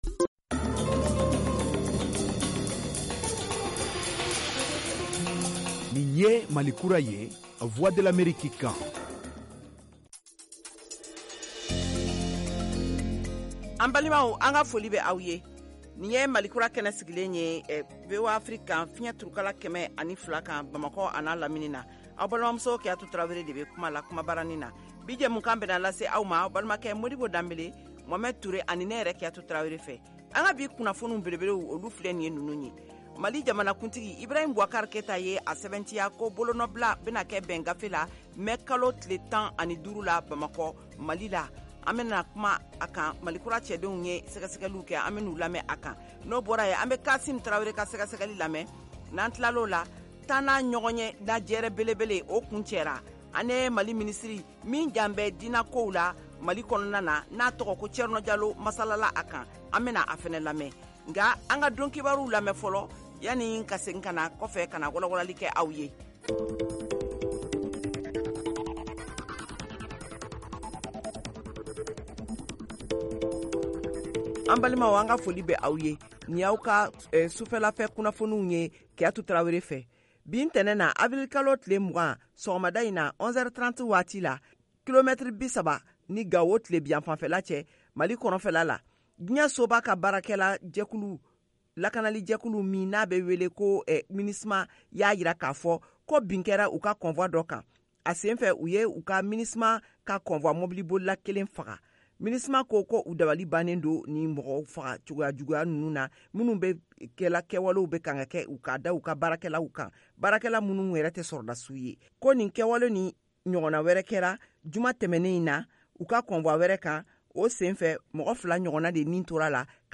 Emission quotidienne en langue bambara
en direct de Washington, DC, aux USA.